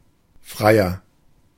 Ääntäminen
Ääntäminen Tuntematon aksentti: IPA: /ˈfʀaɪ̯ɐ/ IPA: /ˈfraɪ.ər/ Haettu sana löytyi näillä lähdekielillä: saksa Käännöksiä ei löytynyt valitulle kohdekielelle. Freier on sanan frei komparatiivi.